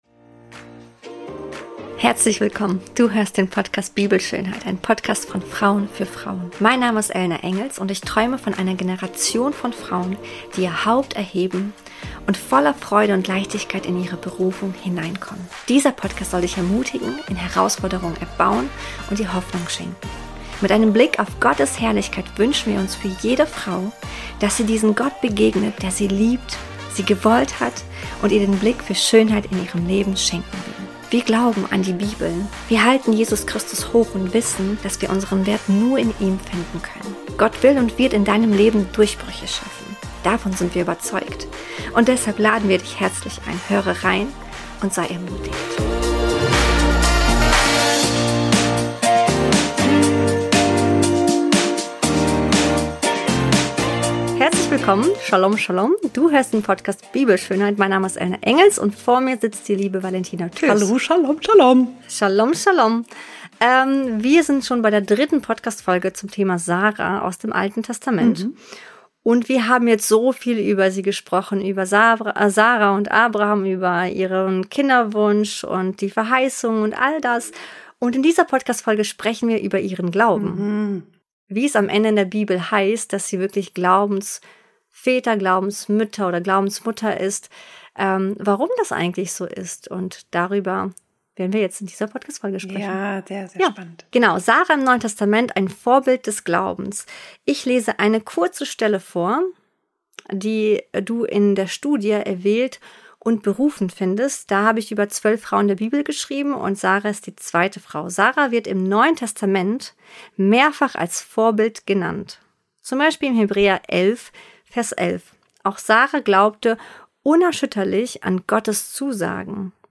Diese Folge mündet in ein Gebet.